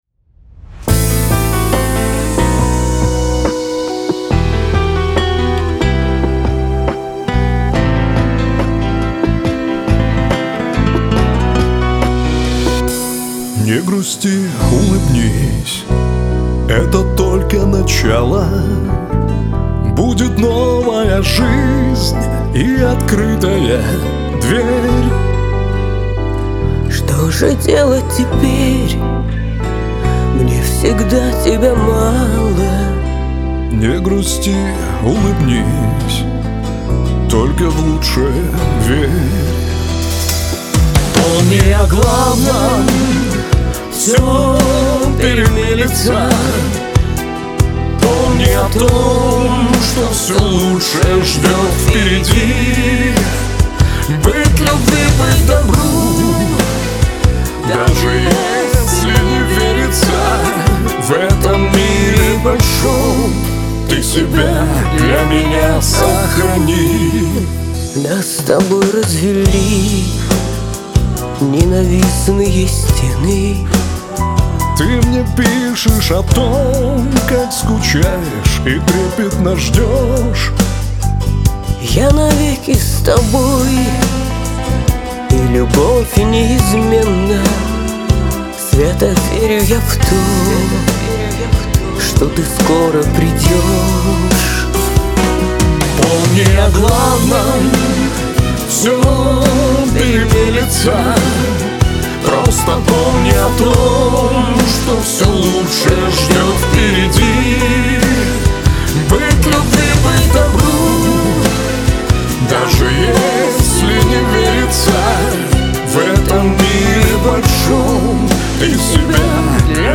Шансон
грусть
Лирика